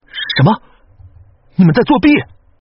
分段配音